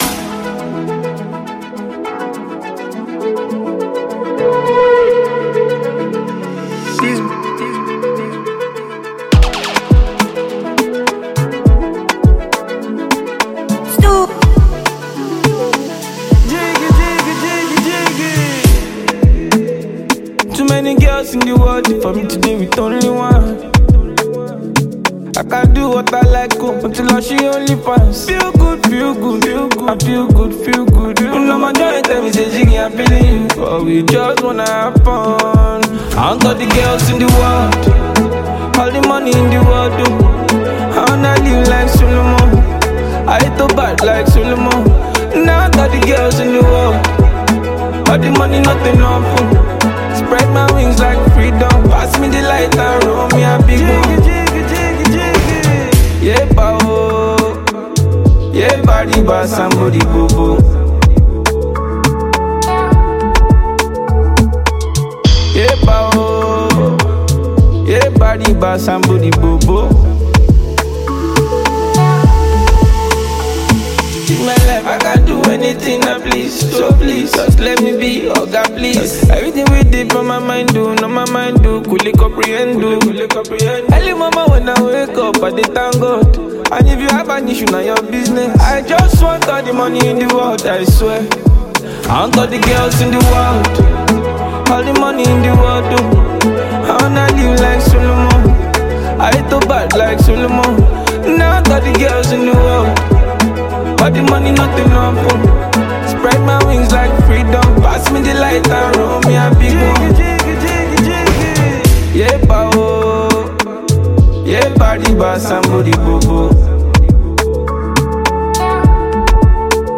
infectious hit record